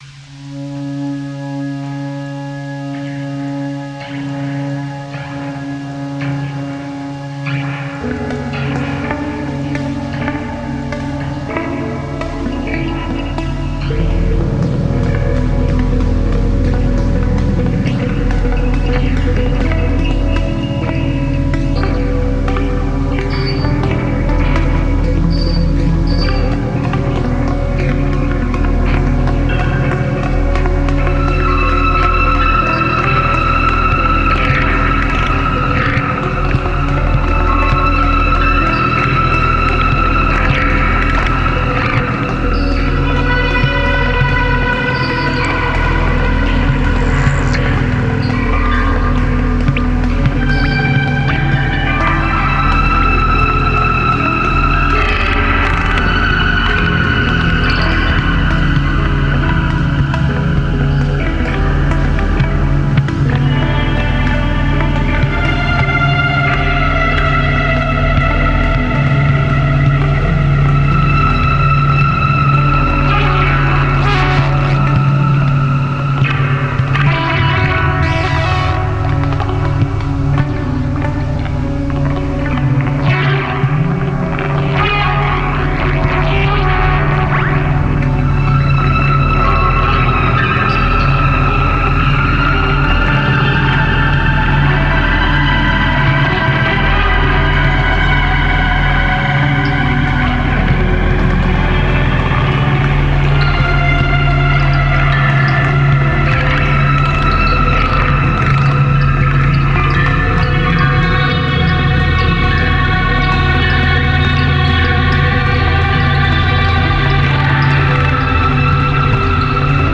声音能量密度大，信号在不同时域里深挖兔子洞。
游浮的振荡器幽灵，释放饱和的锯齿状的声音颗粒，倾泻在洛夫克拉夫特式的宇宙图像中。